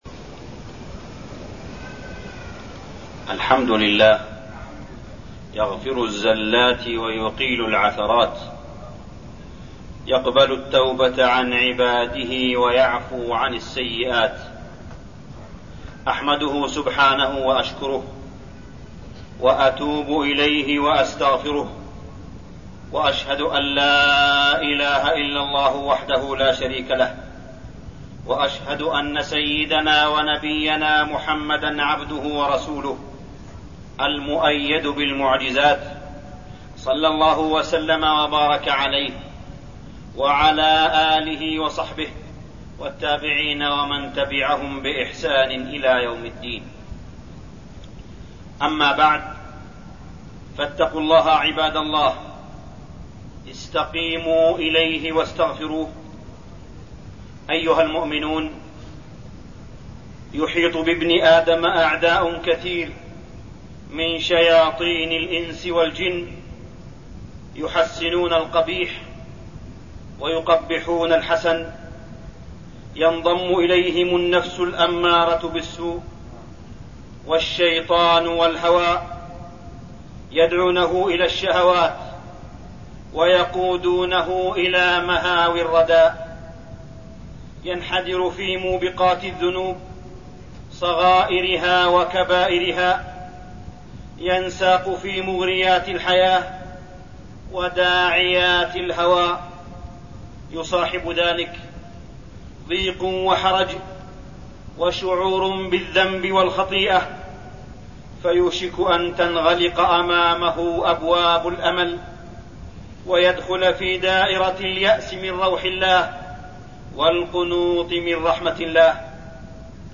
تاريخ النشر ٣٠ ربيع الثاني ١٤٠٩ هـ المكان: المسجد الحرام الشيخ: معالي الشيخ أ.د. صالح بن عبدالله بن حميد معالي الشيخ أ.د. صالح بن عبدالله بن حميد التوبة النصوح